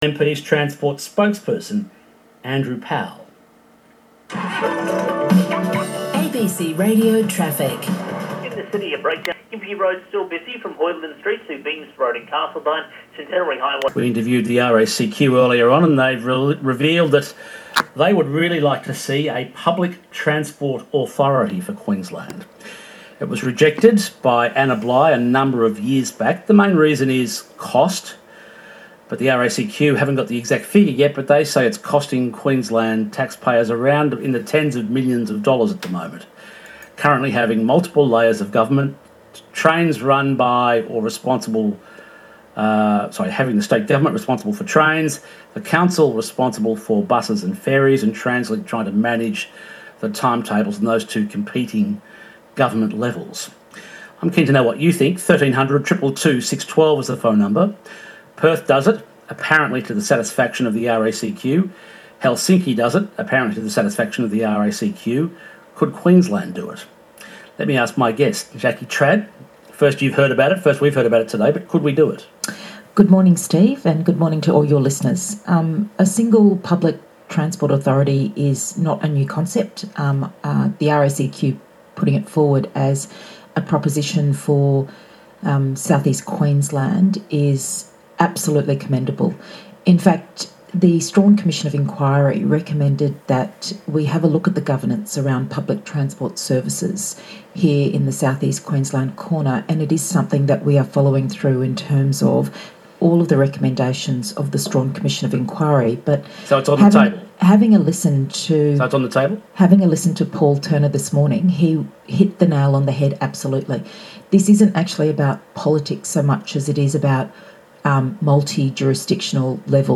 ====================== Extracted interview with Ms Trad and Mr Powell > here!